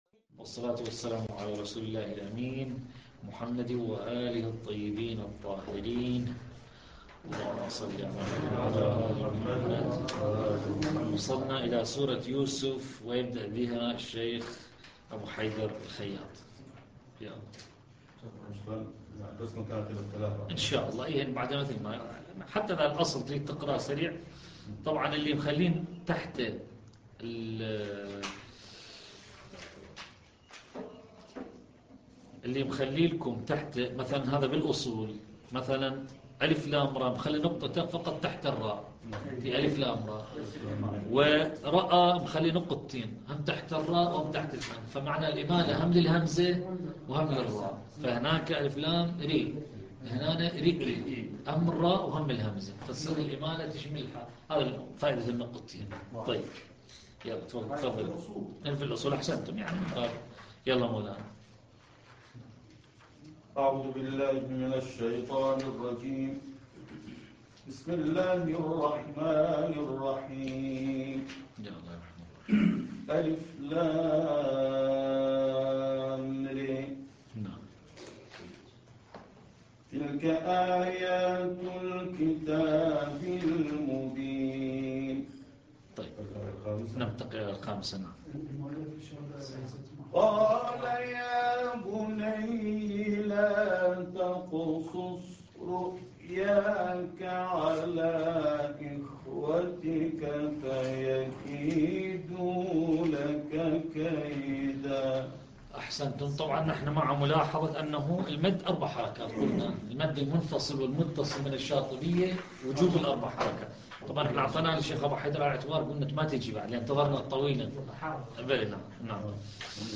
الدرس التاسع عشر - لحفظ الملف في مجلد خاص اضغط بالزر الأيمن هنا ثم اختر (حفظ الهدف باسم - Save Target As) واختر المكان المناسب